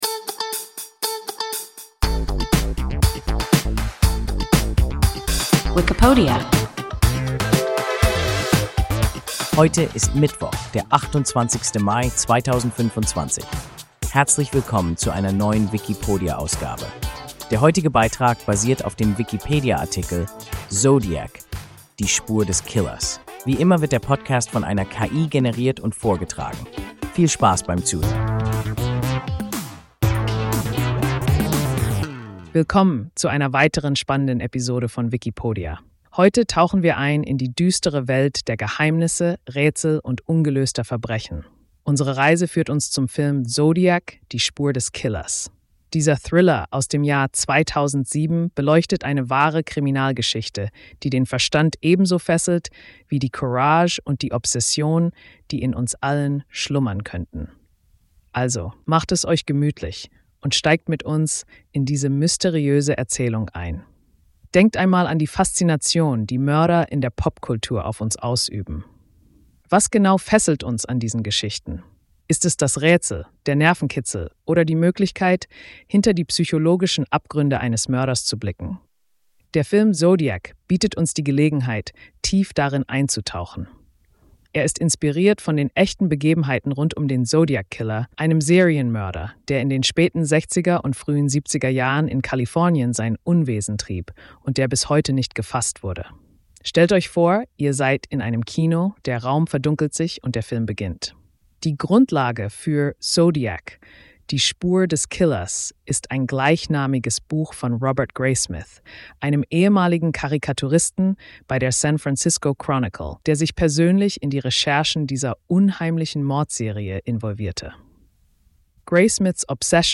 Zodiac – Die Spur des Killers – WIKIPODIA – ein KI Podcast